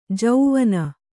♪ jauvana